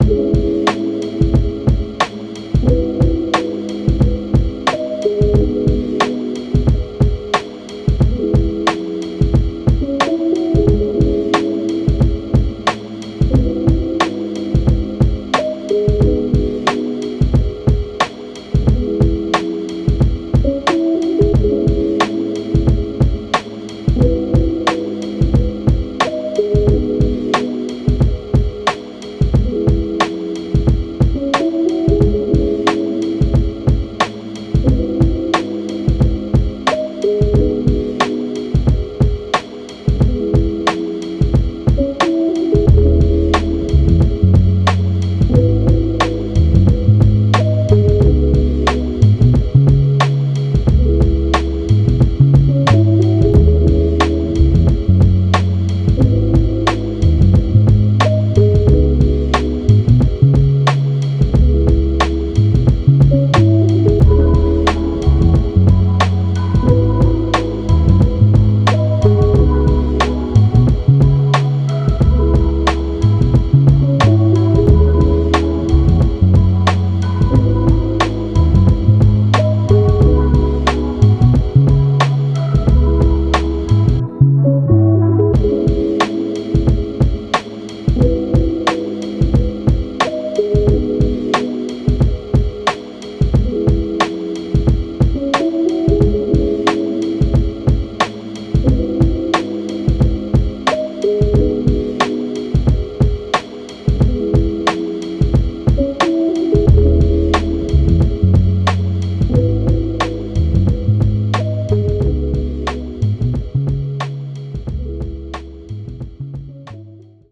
R&B, Hip Hop
C# Minor